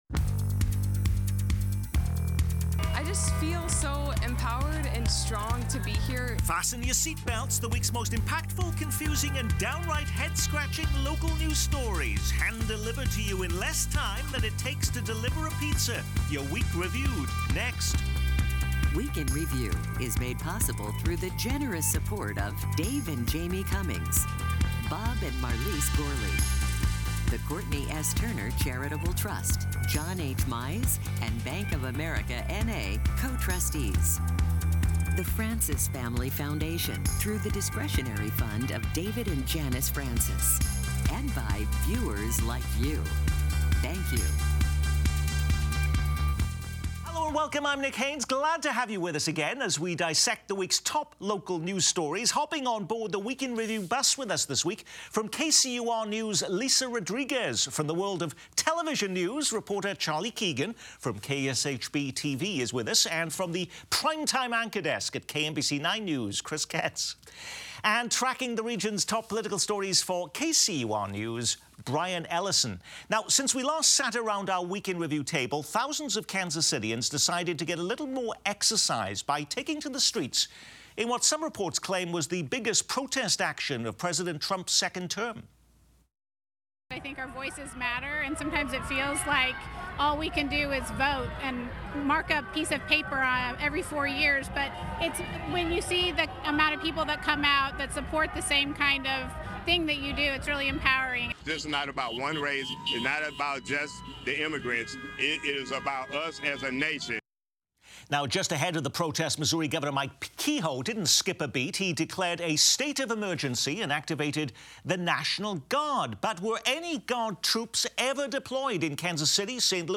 Kansas City Week In Review offers an in-depth view on the top stories of the week with newsmakers and guest experts sharing their insight and perspective.